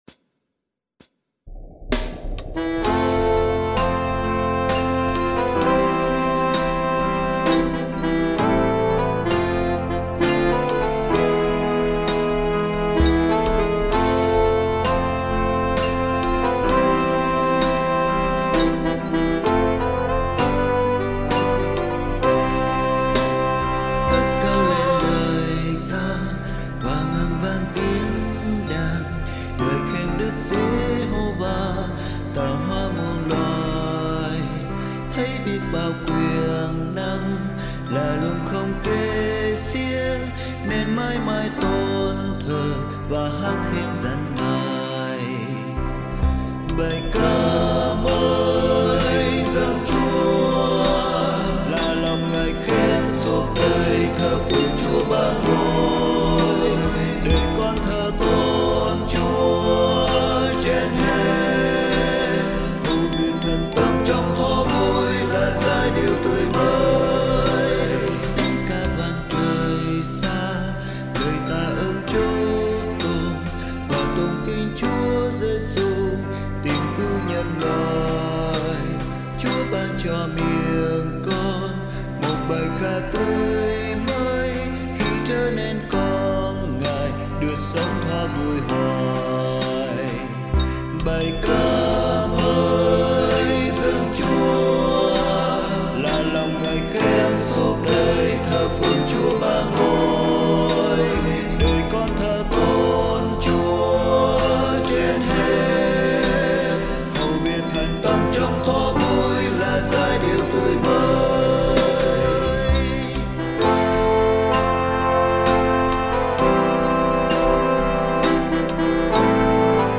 Nhạc Sáng Tác Mới